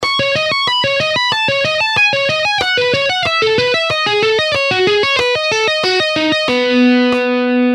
By combining the use of the pick and fingers, hybrid picking enables players to achieve a rich and dynamic sound that can elevate their playing to new heights.
Hybrid-Picking-Exercise-4.mp3